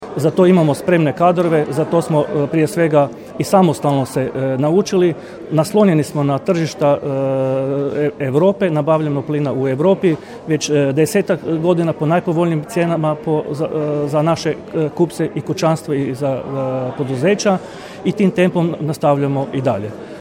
Međimuje plin izborna skupština, Čakovec 17.11.2021.